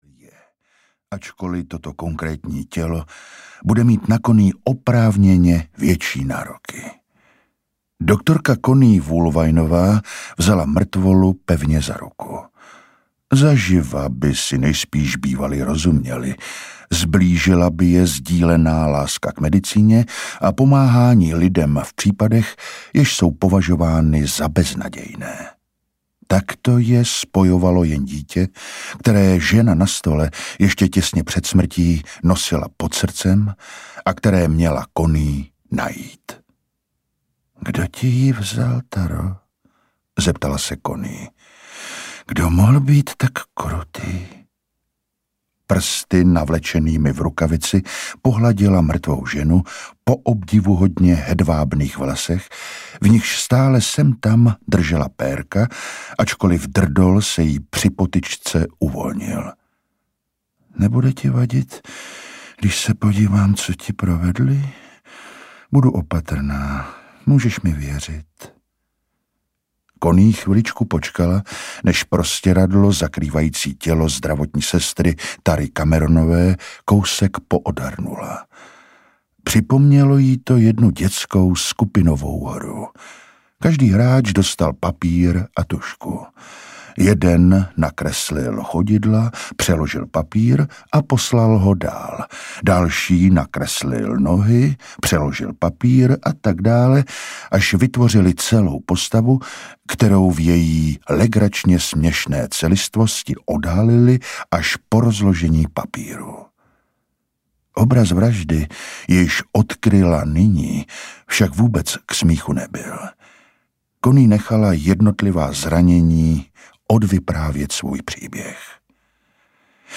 Léčebna audiokniha
Ukázka z knihy